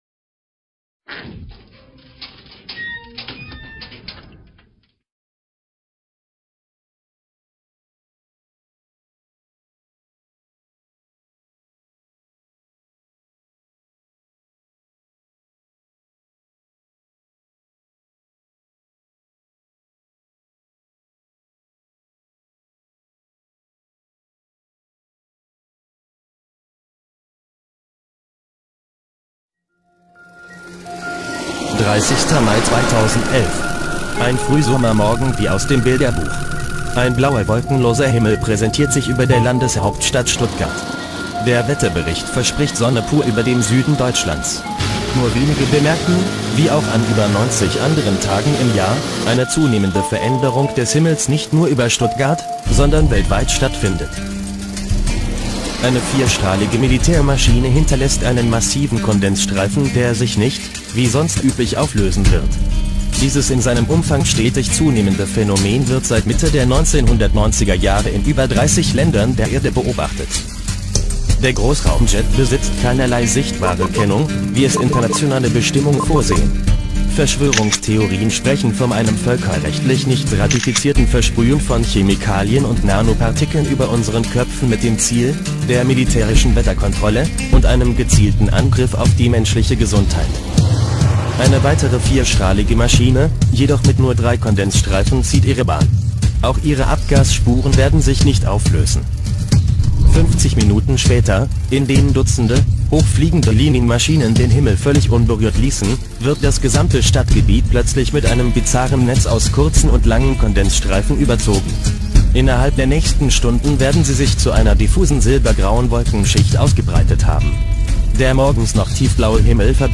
Etwas nervig ist die Computerstimme. Viele Argumente der Chemtrail-Skeptiker und Leugner werden aufgegriffen und widerlegt.